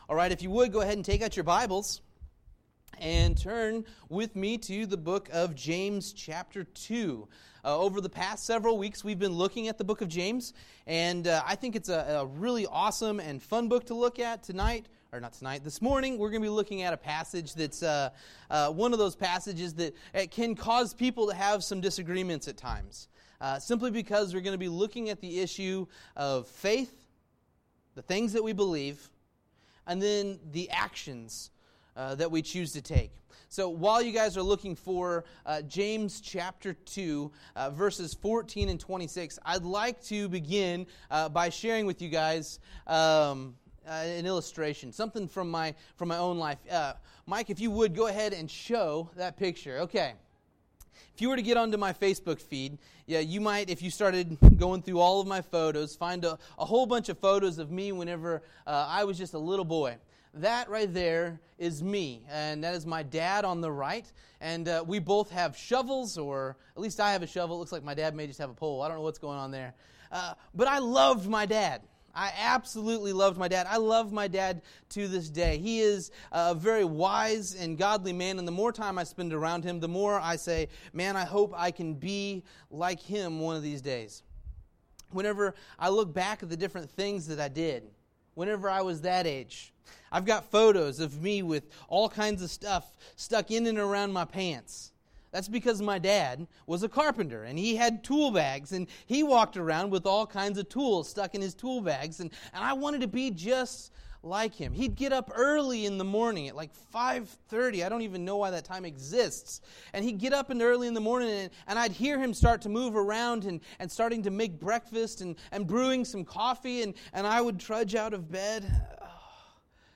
James 2:14-26 Service Type: Sunday Morning Happy Father's Day!